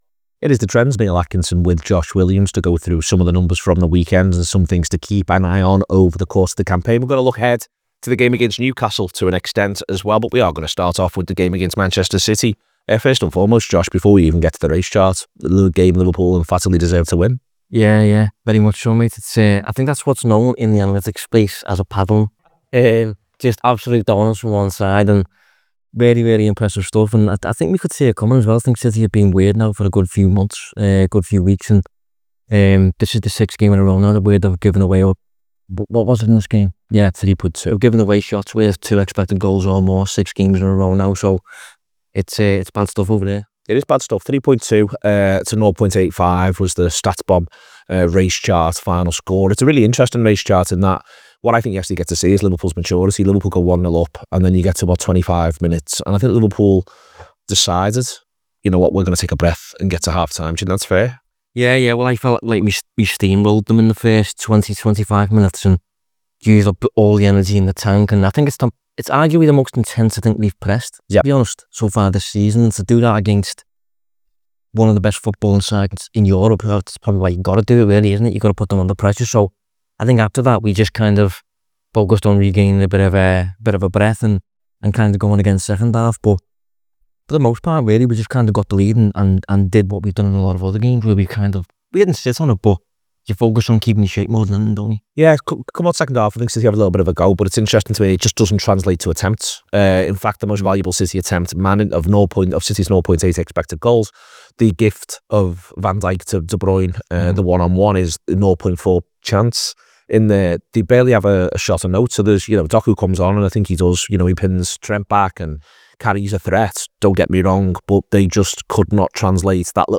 Below is a clip from the show – subscribe for more after another massive win for Liverpool…